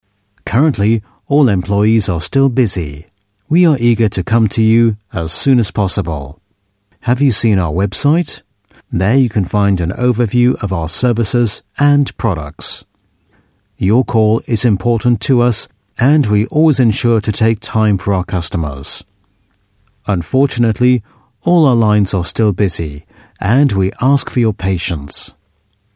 Native Speaker
Englisch (UK)
Telefonansagen